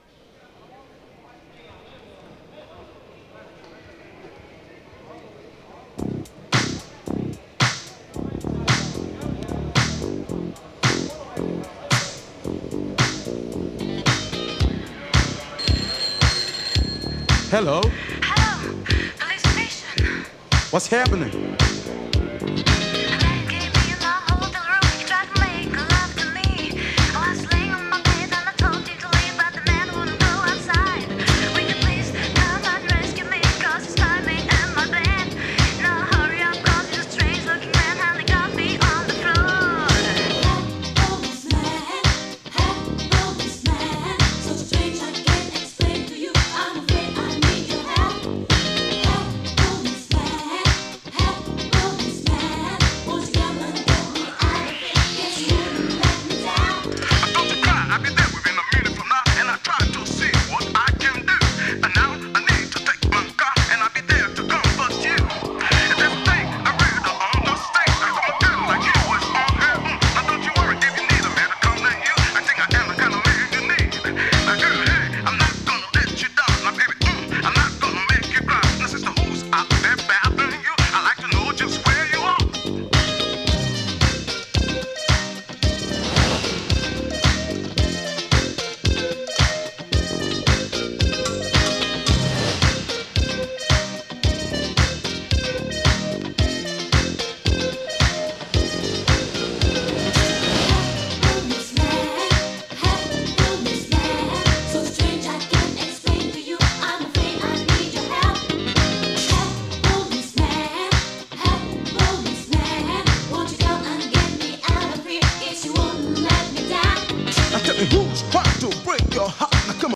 ユーロ オールドスクール
SOUL FUNK HIP HOP RAP
＊A面のアウトロに極軽いチリパチ・ノイズ。